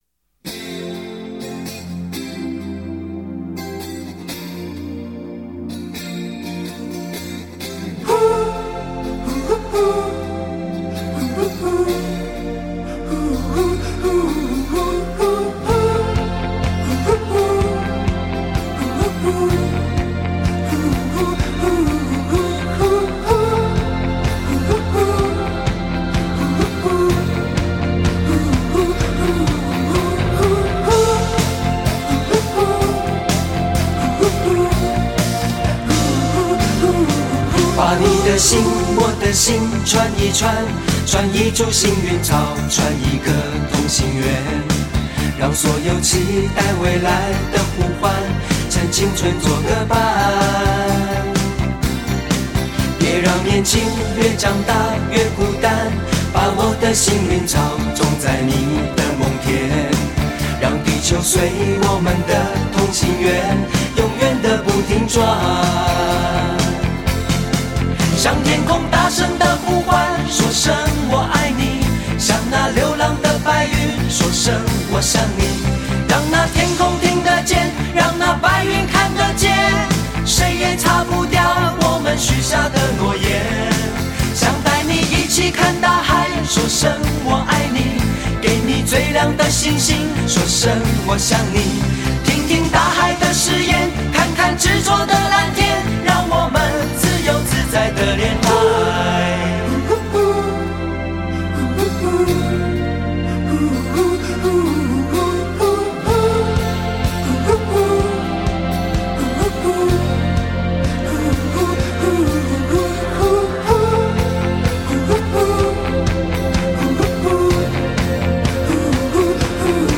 歌曲轻快浪漫